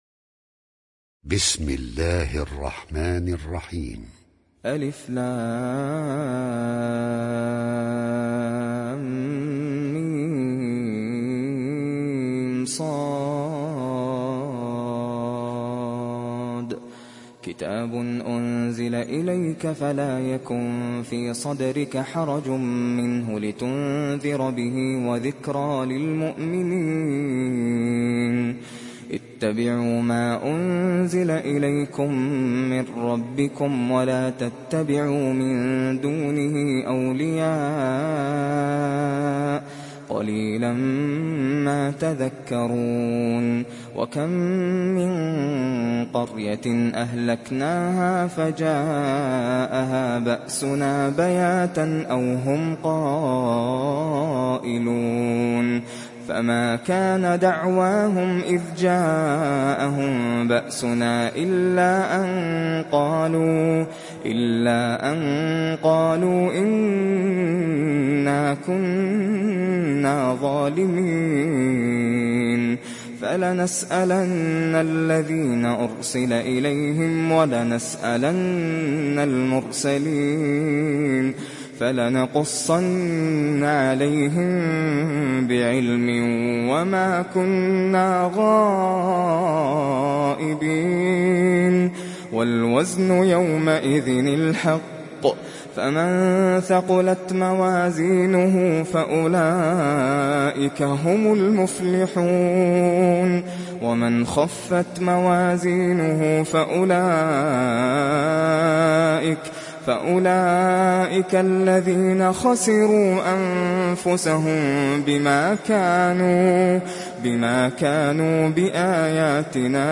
সূরা আল-আ‘রাফ ডাউনলোড mp3 Nasser Al Qatami উপন্যাস Hafs থেকে Asim, ডাউনলোড করুন এবং কুরআন শুনুন mp3 সম্পূর্ণ সরাসরি লিঙ্ক